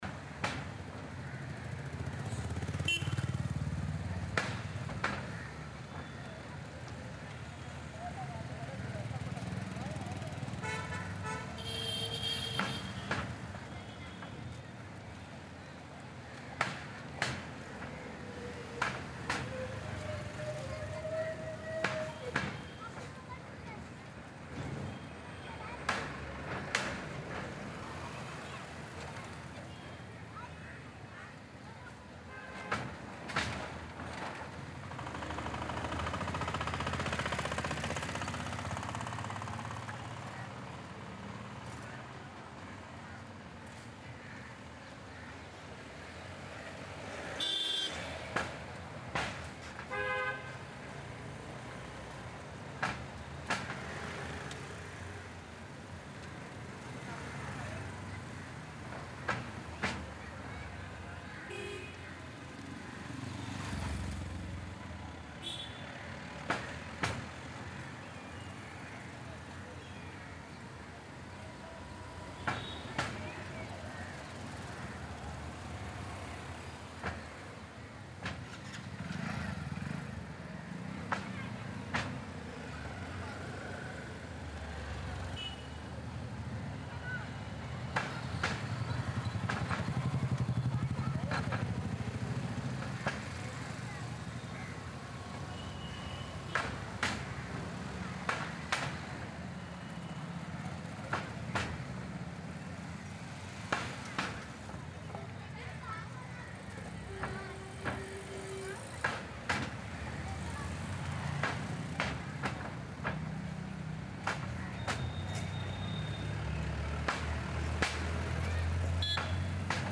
Slumyard Construction captures the raw, unfiltered atmosphere of small-scale building and repair work inside a densely populated slum or chawl area. This ambience blends light brick-cutting, hammer taps, distant drilling, workers talking softly, metal clanks, footsteps, and natural neighbourhood noise. The sound reflects real-life local construction activity—busy, gritty, and authentic without being overwhelmingly loud.
Urban / Construction
Local Building Work
Medium Mechanical
Outdoor Community Field
Forty-slumyard-construction.mp3